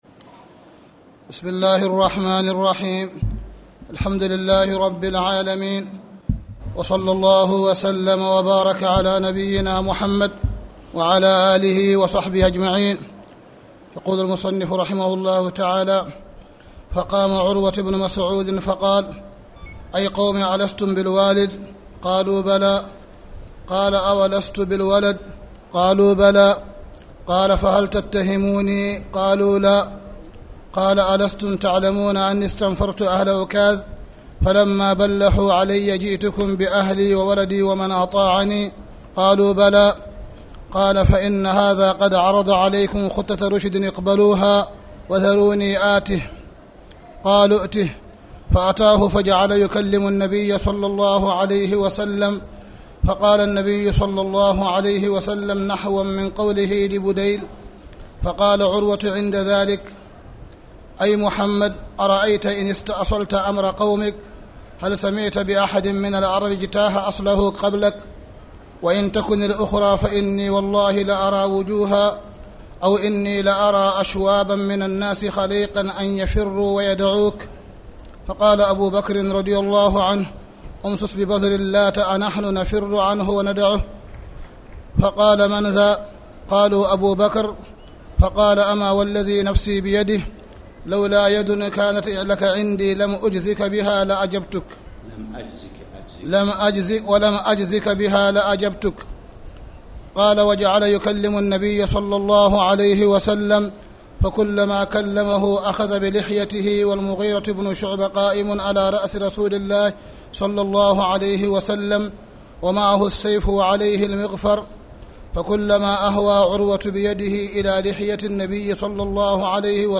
تاريخ النشر ٢٦ شعبان ١٤٣٨ المكان: المسجد الحرام الشيخ: معالي الشيخ أ.د. صالح بن عبد الله بن حميد معالي الشيخ أ.د. صالح بن عبد الله بن حميد كتاب الجهاد